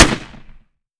7Mag Bolt Action Rifle - Gunshot A 004.wav